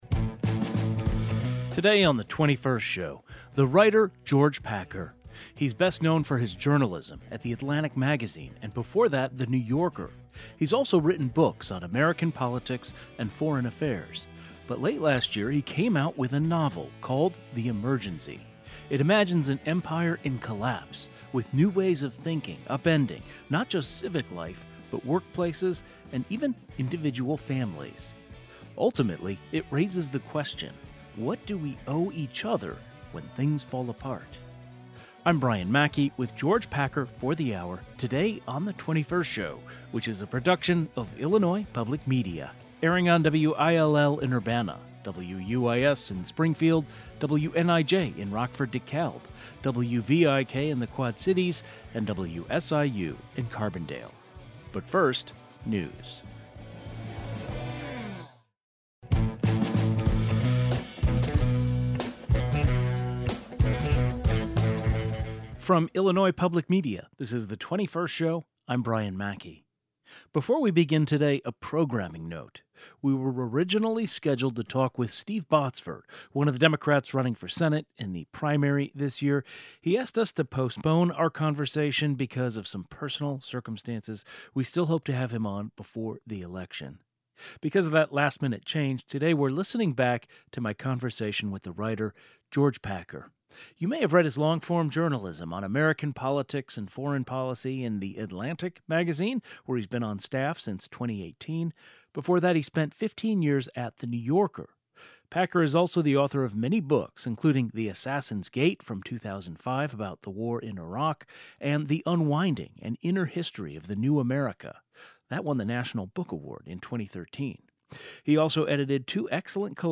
Today's show included a rebroadcast of the following "best of" segment, first aired November 14, 2025: George Packer on ‘The Emergency’.
Tags george packer the emergency author interviews author interview